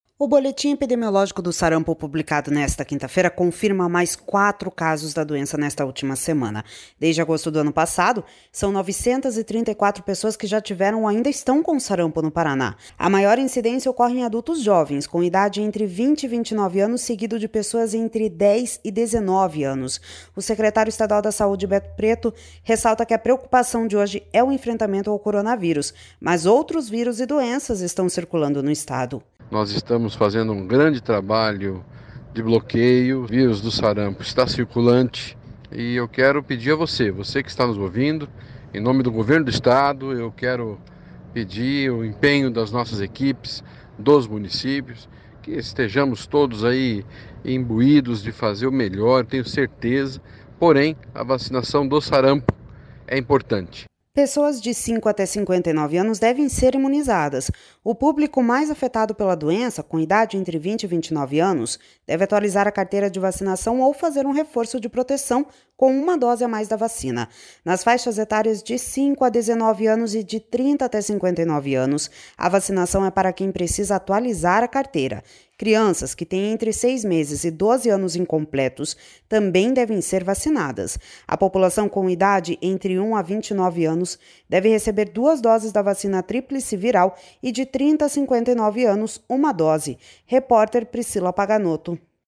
O secretário estadual da Saúde, Beto Preto, ressalta que a preocupação de hoje é o enfrentamento ao coronavírus, mas outros vírus e doenças estão circulando no Estado.// SONORA BETO PRETO//